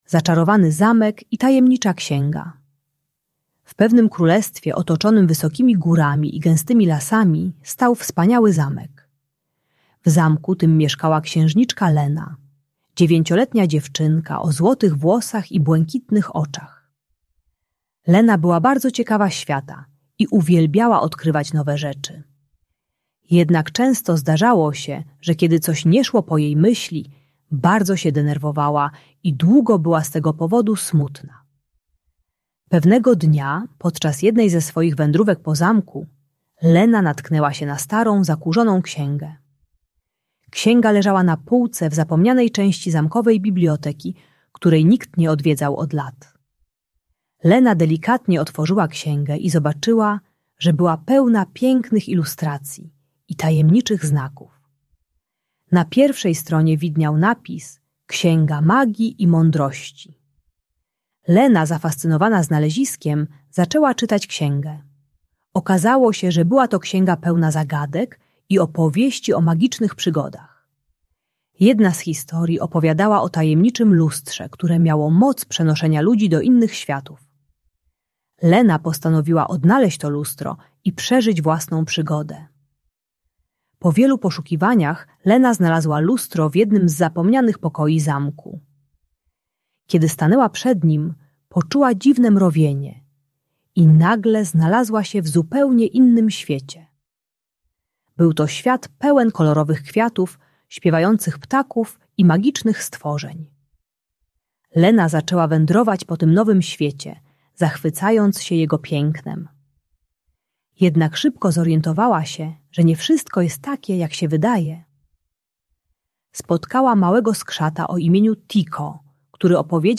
Zaczarowany Zamek i Tajemnicza Księga - Niepokojące zachowania | Audiobajka